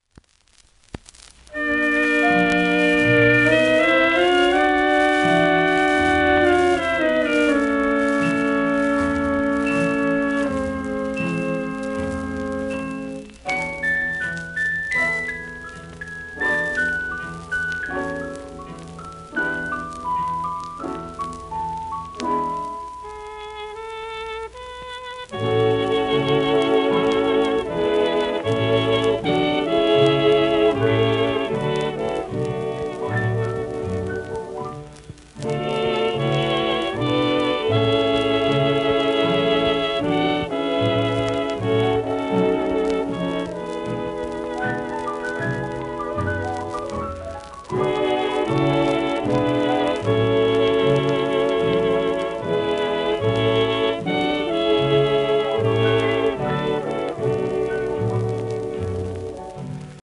盤質:A- *ス小キズ,ややサーフェイスノイズ
1940年代の録音を1950年代に電蓄用に再プレスしたレコード